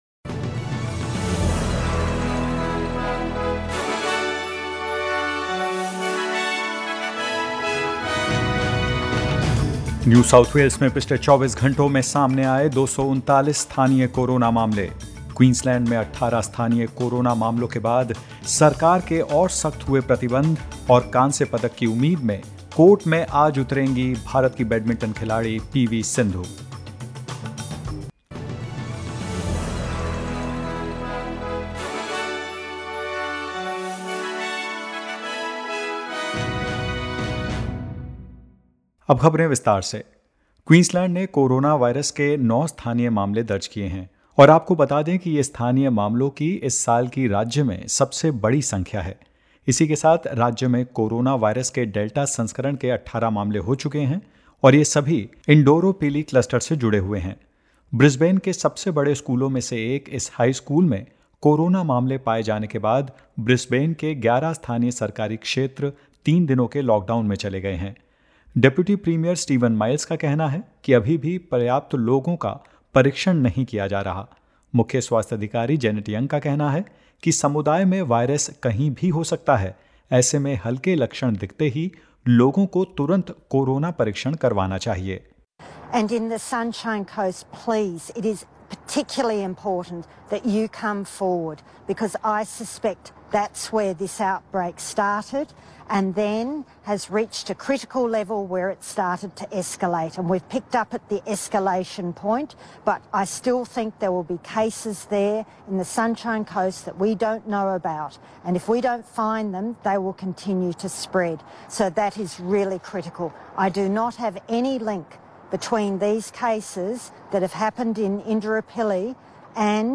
In this latest SBS Hindi News bulletin of Australia and India: The total number of Delta cases in Queensland is now 18; Victoria’s four new cases are linked to existing outbreaks and more.